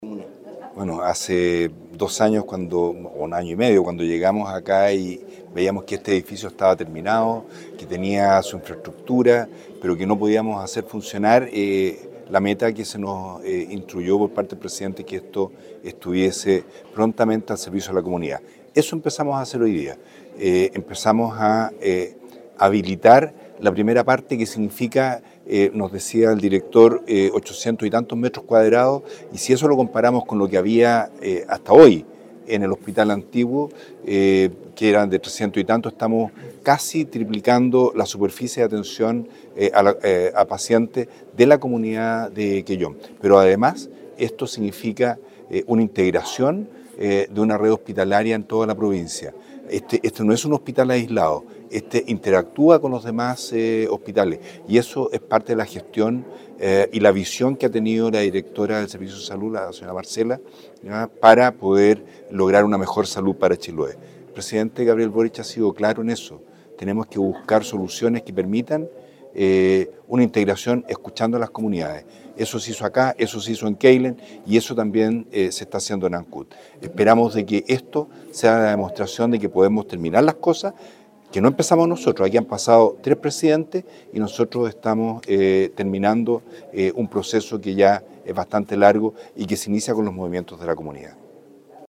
Para dar a conocer los alcances de esta apertura gradual orientada a fortalecer el acceso y entregar atenciones con mejores condiciones de calidad y seguridad, se realizó una instancia informativa en el segundo piso del Nuevo Hospital de Quellón, que contó con la participación de autoridades provinciales y comunales, representantes de la comunidad y equipos de salud.
Al respecto, el Delegado presidencial provincial de Chiloé, Marcelo Malagueño, valoró el inicio del Policlínico de Especialidades del nuevo hospital quellonino, que representa un avance concreto en el cumplimiento del compromiso presidencial de poner en funcionamiento los nuevos hospitales en el país.